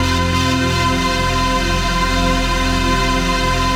RAVEPAD 03-LR.wav